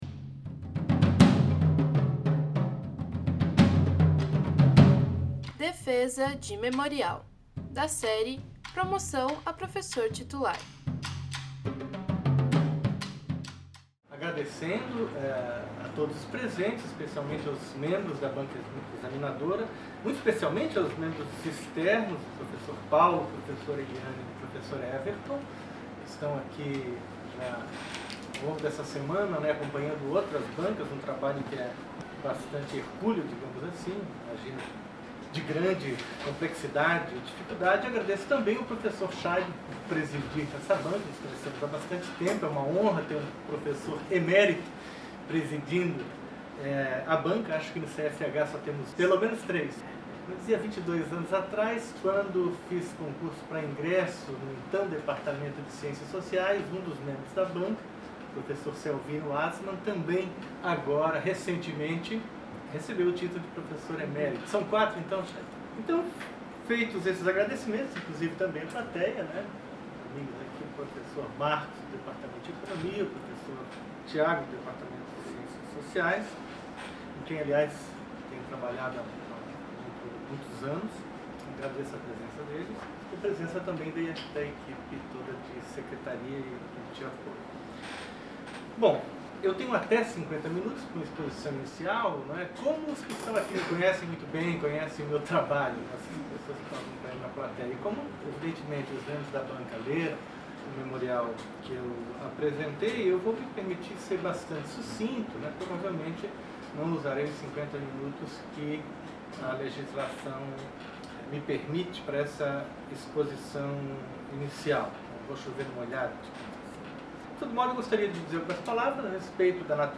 Áudio da defesa do Memorial para promoção à Titular de Carreira
no Auditório do MArquE